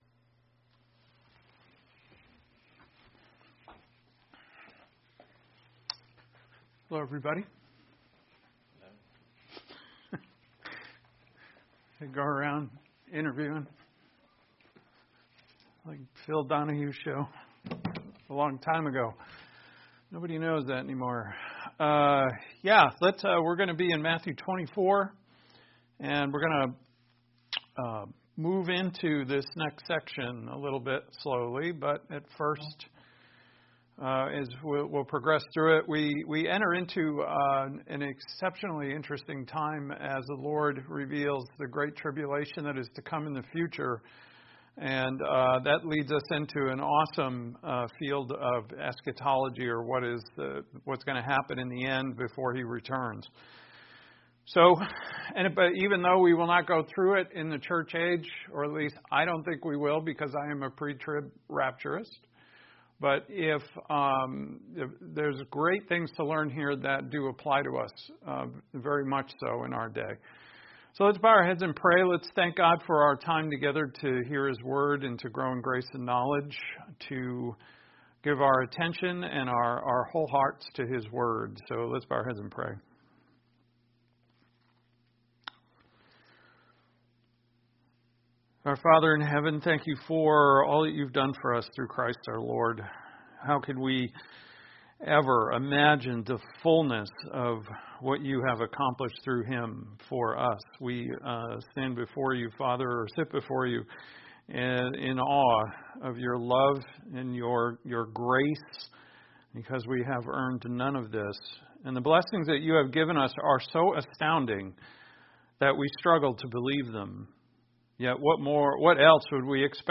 Grace and Truth Ministries is non-denominational